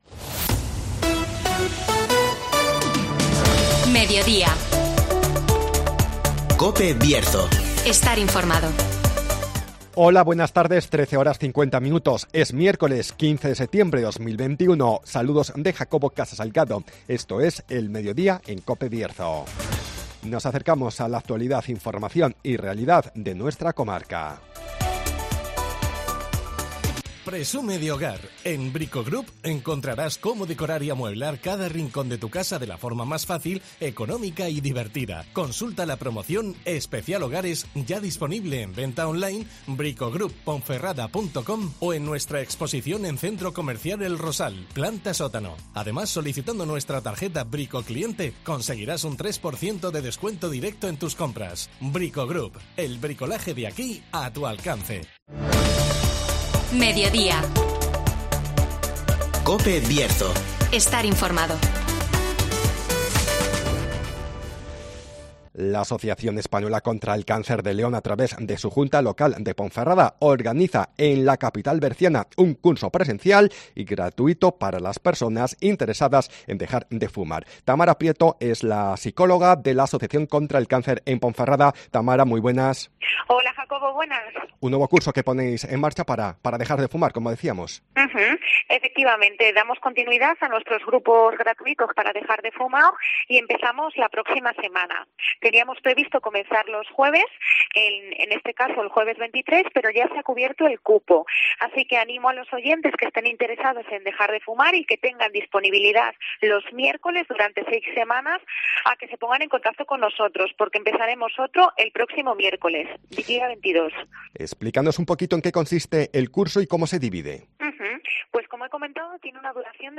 AECC León programa en Ponferrada un curso gratuito para ayudar a dejar de fumar (Entrevista